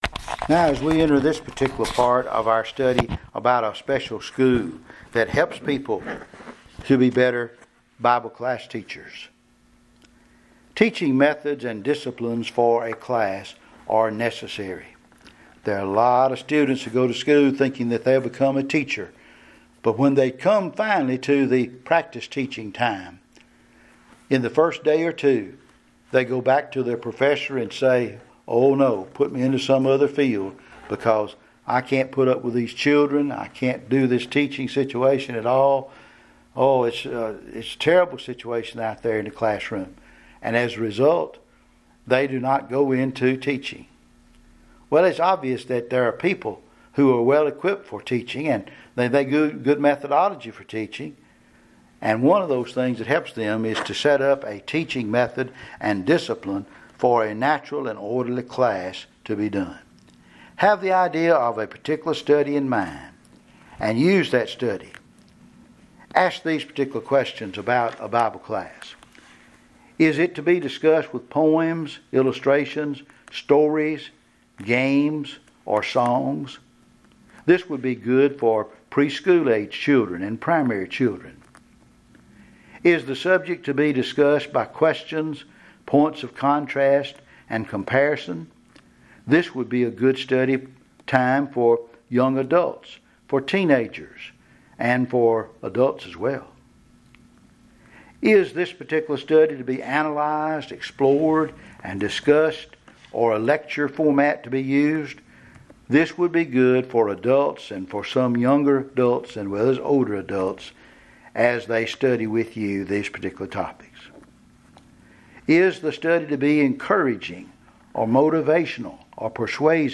Setting-Up-1b-Preaching.mp3